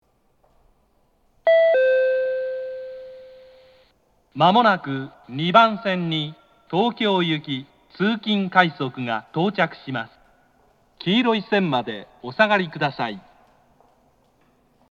京葉型（男性）
接近放送